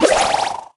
ninja_star_01.ogg